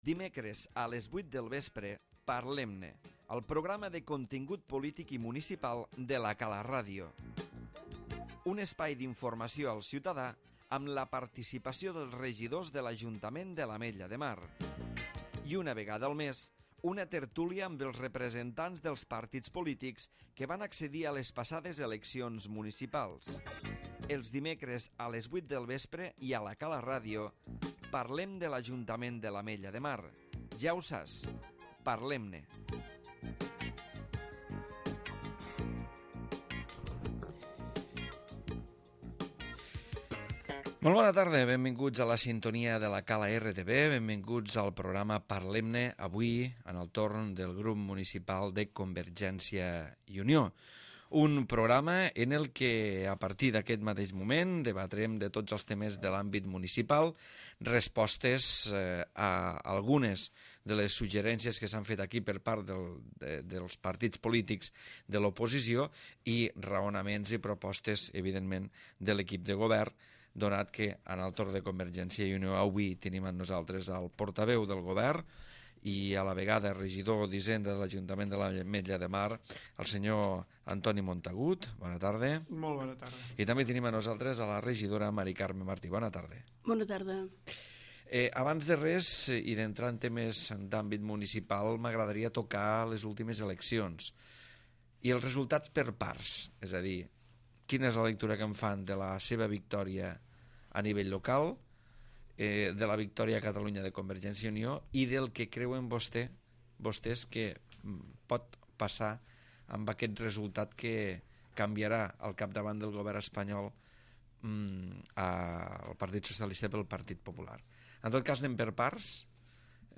Antoni Montagut i M Carme Martí, regidors del Grup Municipal de CiU han participat avui al programa Parlem-ne.